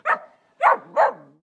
Ladridos de un perro pequeño 01